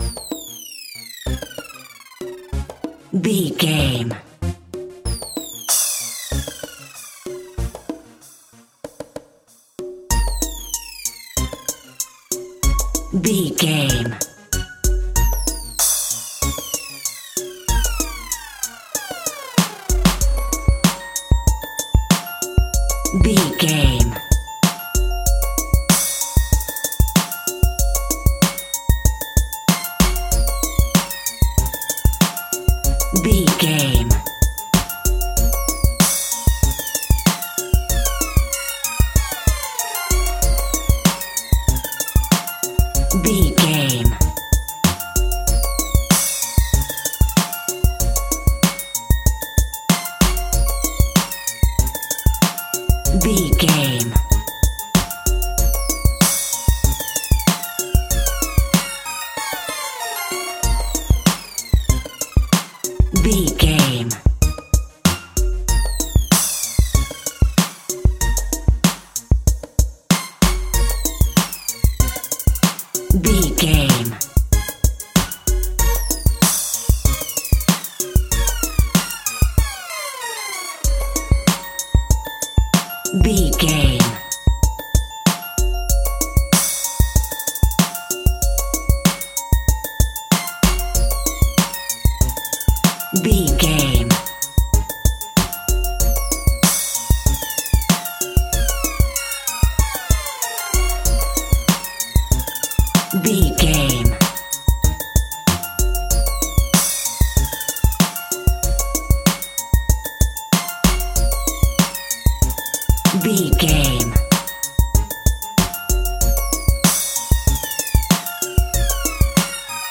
Aeolian/Minor
synthesiser
drum machine
hip hop
Funk